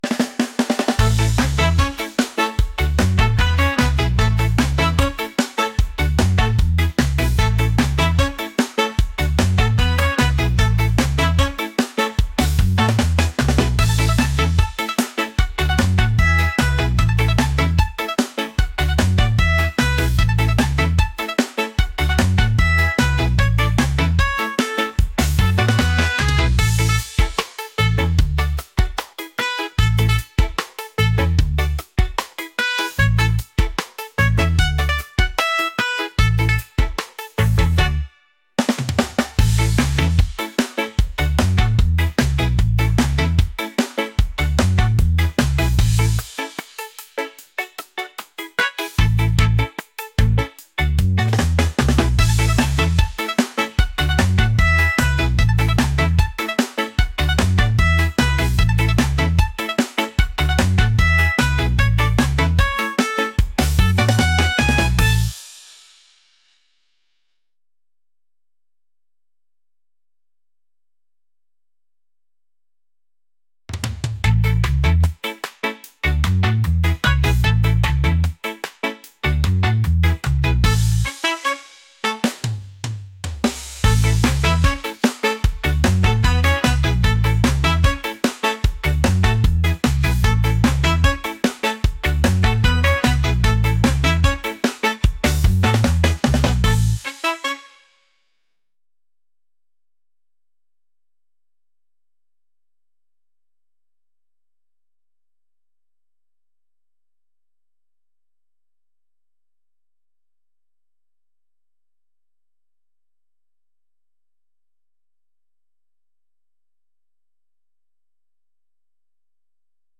upbeat | reggae | energetic